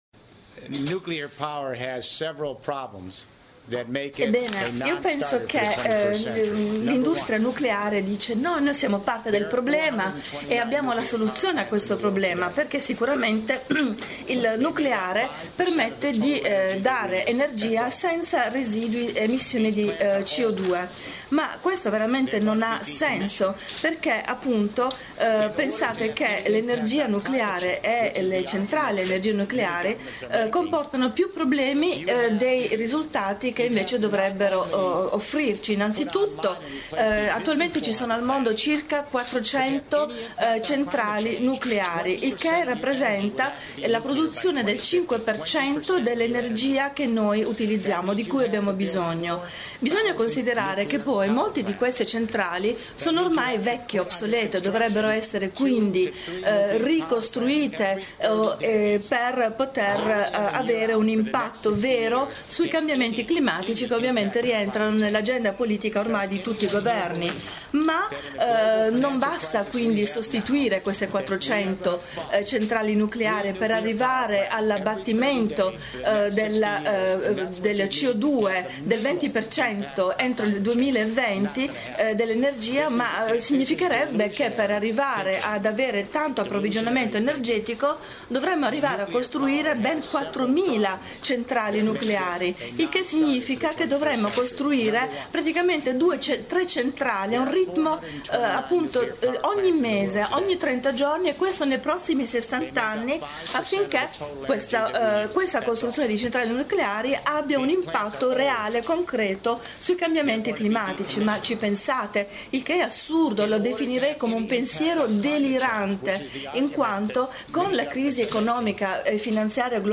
Trad. simultanea